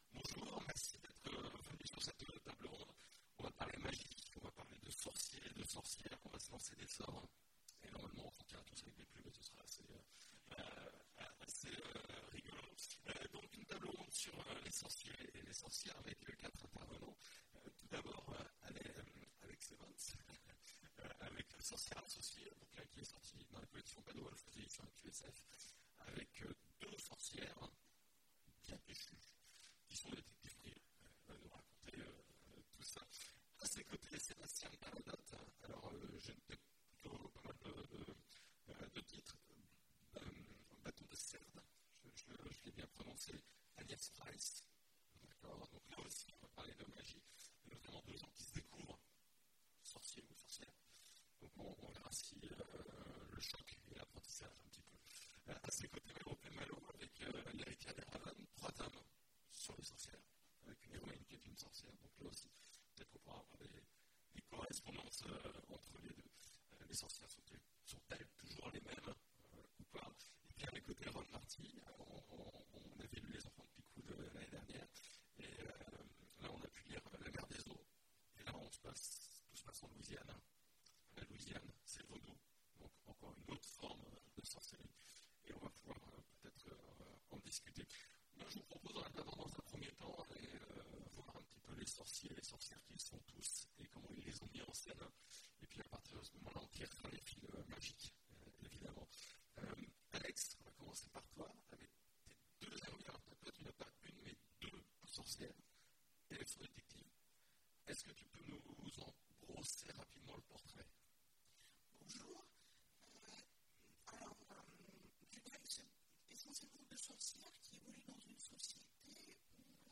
Imaginales 2017 : Conférence Sorciers ! Et autres jeteurs de sorts...